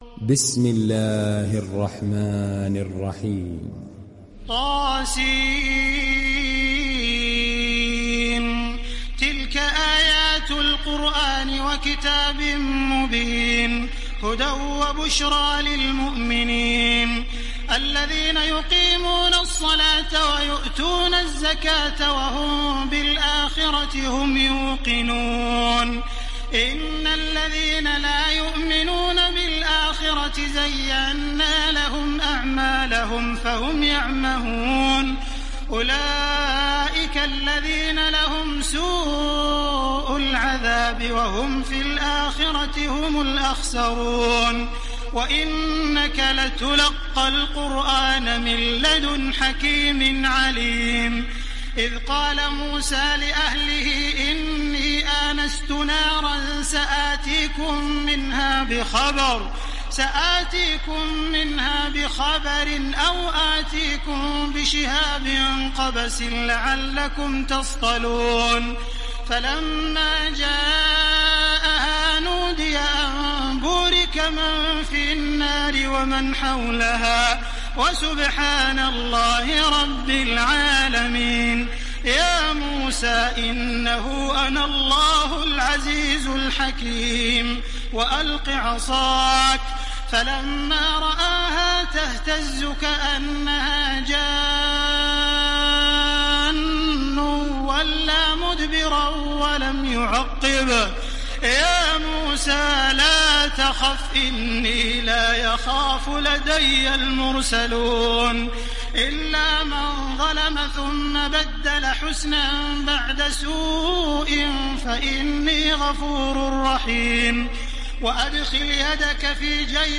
Neml Suresi İndir mp3 Taraweeh Makkah 1430 Riwayat Hafs an Asim, Kurani indirin ve mp3 tam doğrudan bağlantılar dinle
İndir Neml Suresi Taraweeh Makkah 1430